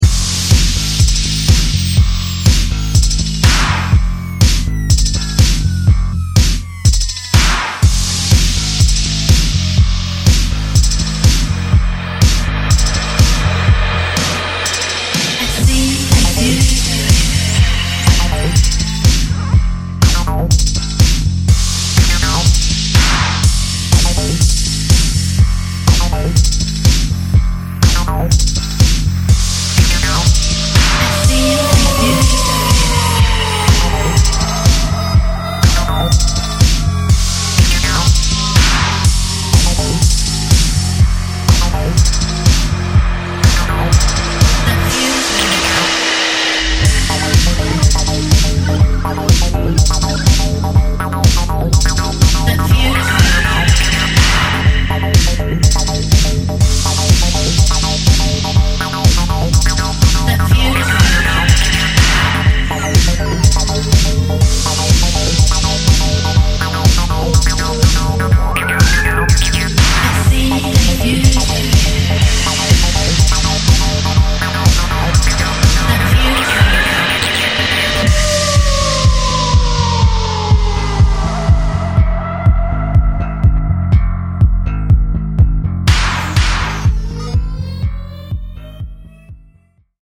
ニューウェイヴ/EBM/アシッド・ハウス/トライバル等の要素を退廃的に昇華した、濃厚なデビュー作となっています。